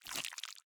Minecraft Version Minecraft Version 25w18a Latest Release | Latest Snapshot 25w18a / assets / minecraft / sounds / item / honeycomb / wax_on3.ogg Compare With Compare With Latest Release | Latest Snapshot